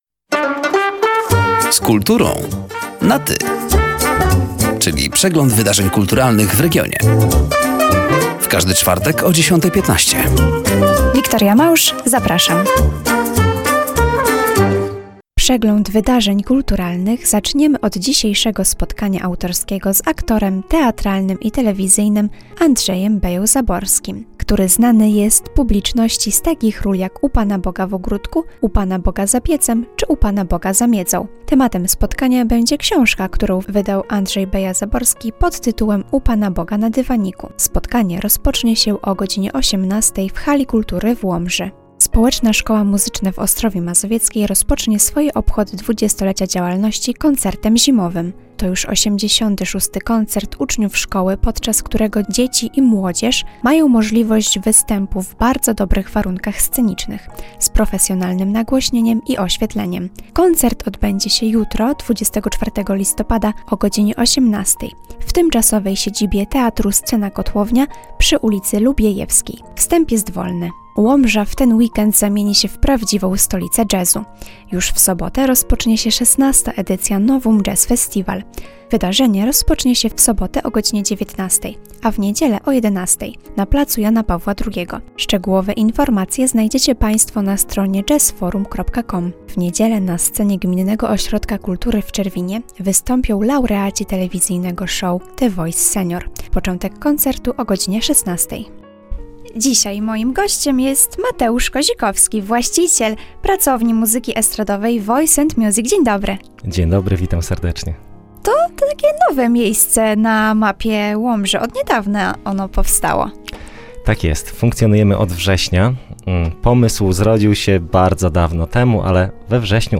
Zapraszamy do zapoznania się ze zbliżającymi się wydarzeniami oraz do wysłuchania rozmowy.